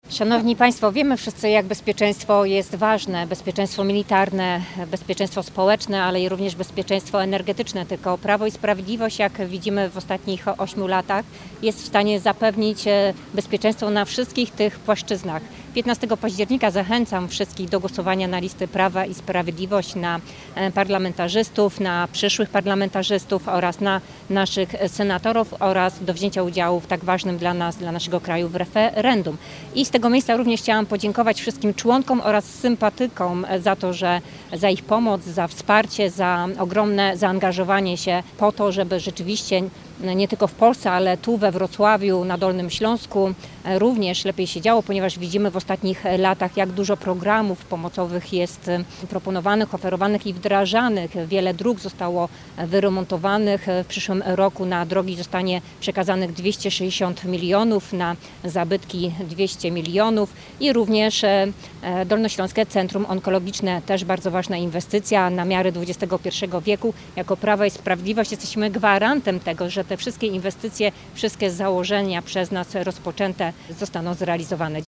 Na Placu Gołębim we Wrocławiu zgromadzili się senatorowie i posłowie, a także osoby ubiegające się o mandat do parlamentu.